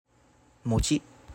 A mochi (/mtʃ/ MOH-chee;[1] Japanese もち, [motɕi]